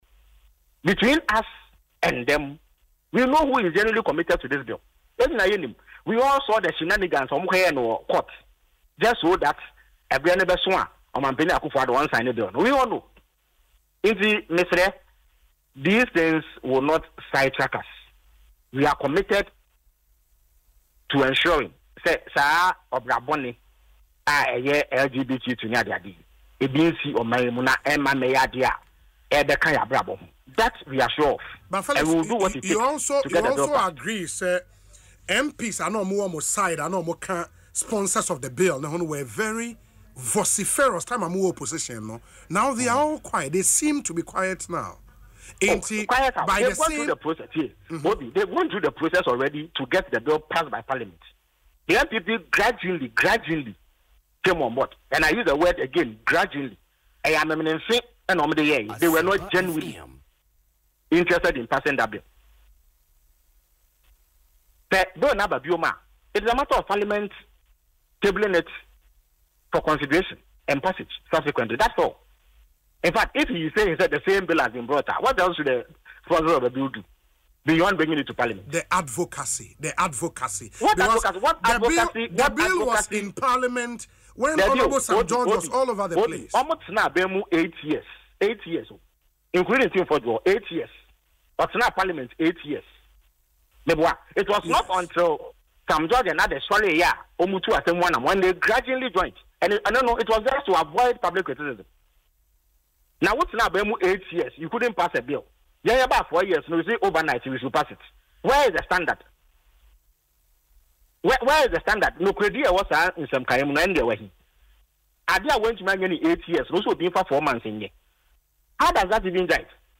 In an interview on Asempa FM’s Ekosii Sen, Mr Ofosu stated that the NPP, under former President Akufo-Addo, had eight years to pass the bill but failed to act.